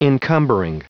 Prononciation du mot encumbering en anglais (fichier audio)
Prononciation du mot : encumbering